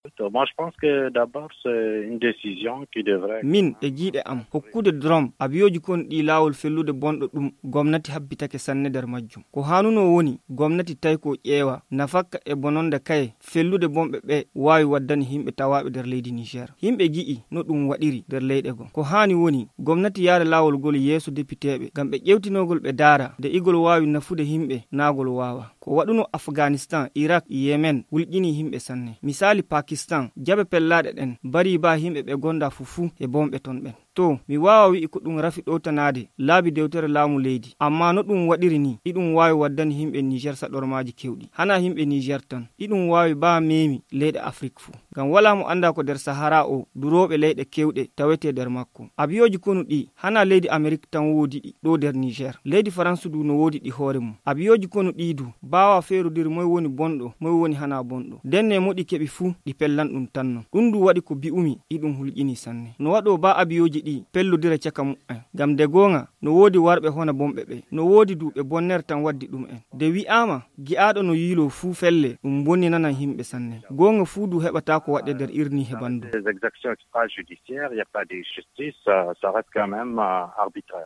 Magazine 03/11/2017 : analyse d’un spécialiste des questions sécuritaires sur l’autorisation accordée par les Autorités Nigériennes aux drones armés américains de frapper des cibles sur le sol nigérien.